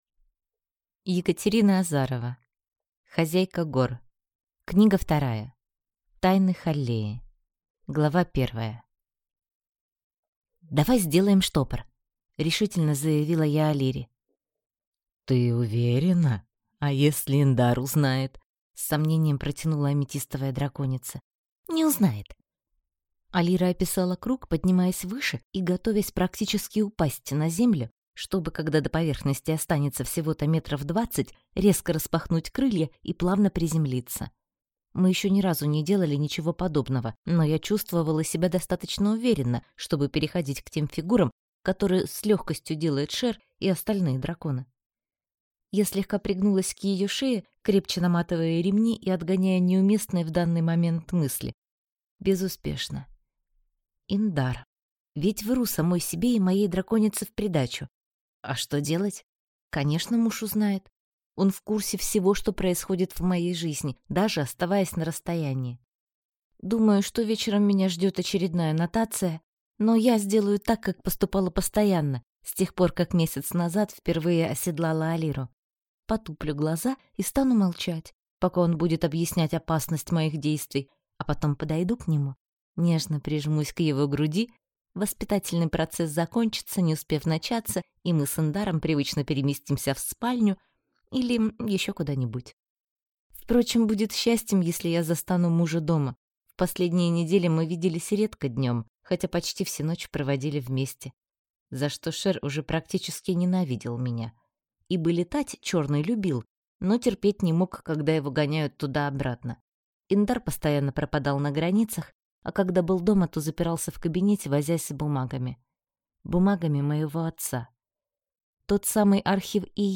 Аудиокнига Хозяйка гор. Тайны Халлеи - купить, скачать и слушать онлайн | КнигоПоиск